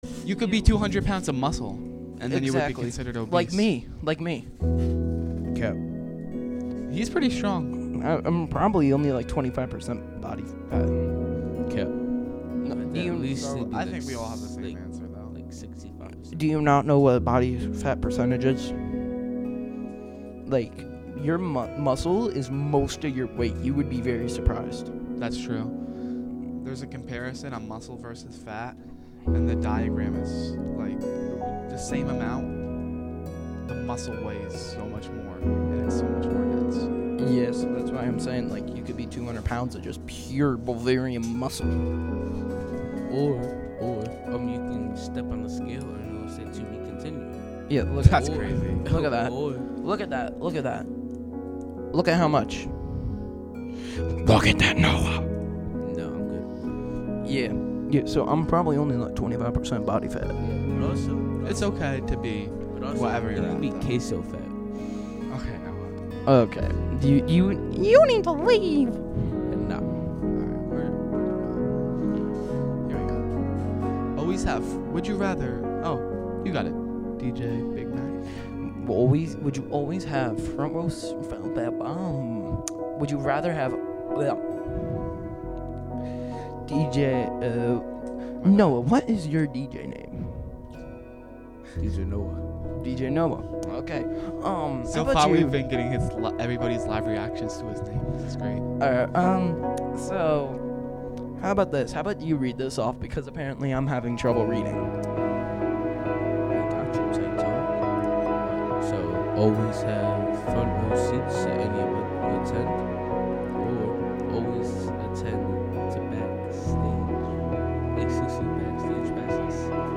Live from the Catskill Clubhouse.